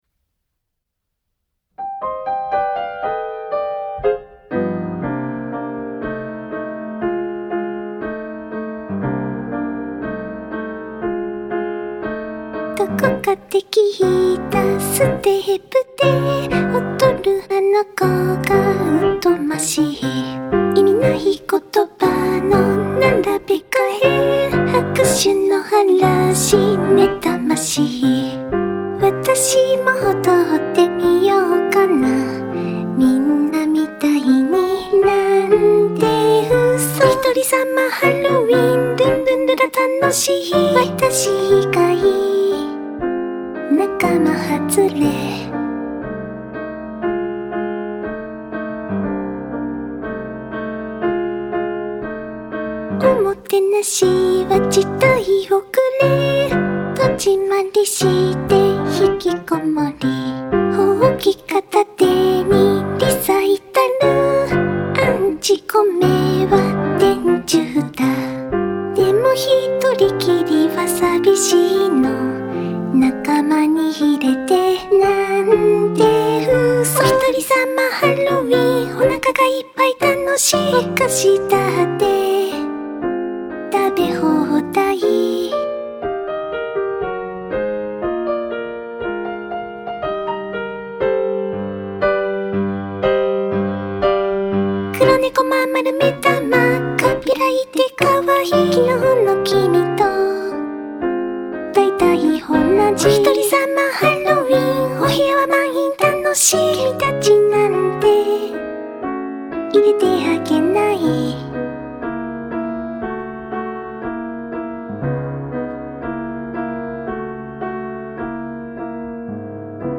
【Vocal / リマスター版2025】 mp3 DL ♪